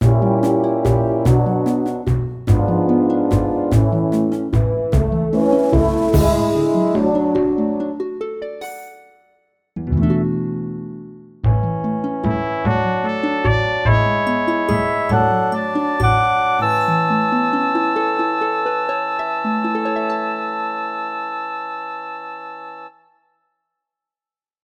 A short song demoing the sounds from the SC-88.